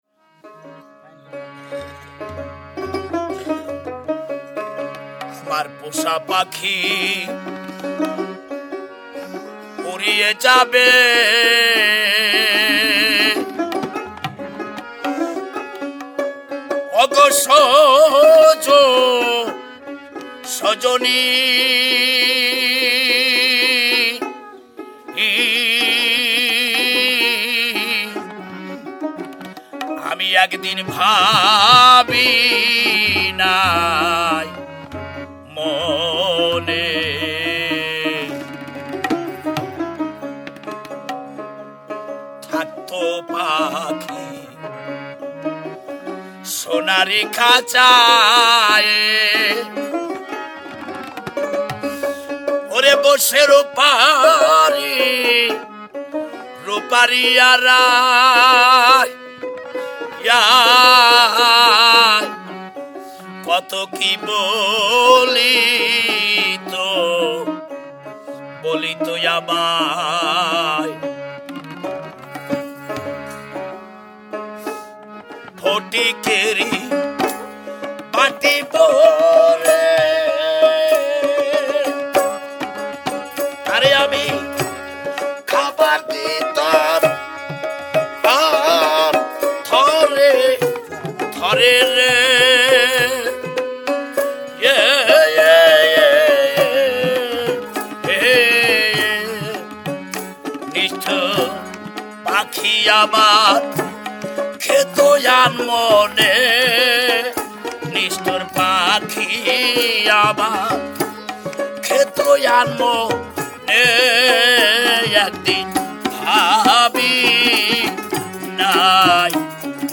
improvised ‘studio’ (which comprises a couple of computers and microphones)
he keeps the basic structure of the song and mixes up the verses.